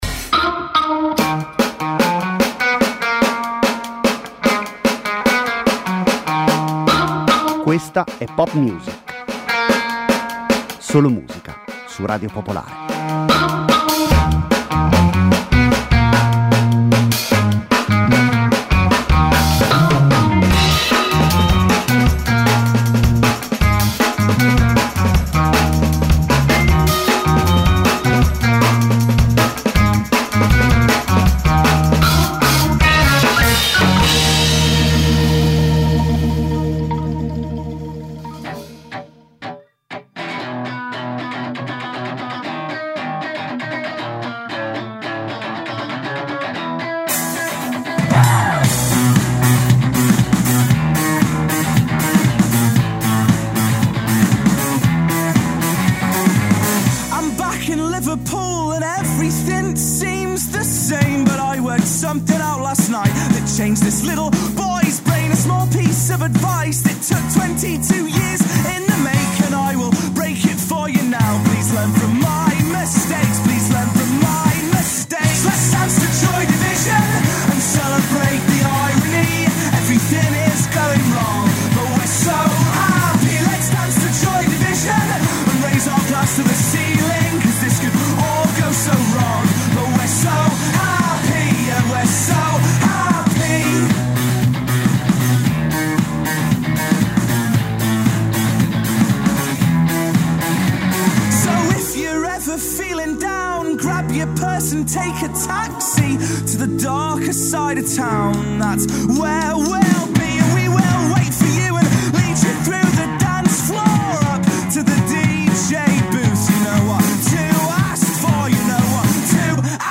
Una trasmissione di musica, senza confini e senza barriere.
Senza conduttori, senza didascalie: solo e soltanto musica.